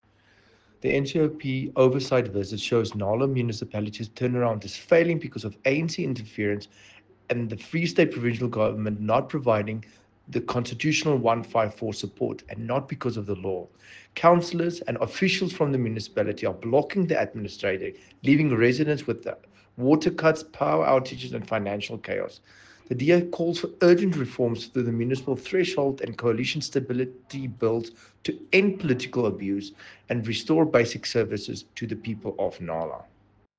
English soundbite by Dr Igor Scheurkogel MP,